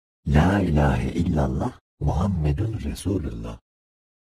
la-ilahe-illallah-diyen-kurbaga.mp3